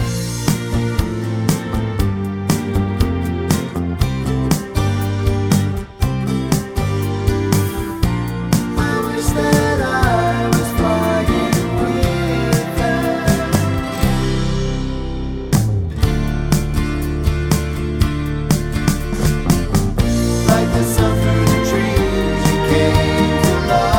no Backing Vocals Soundtracks 4:14 Buy £1.50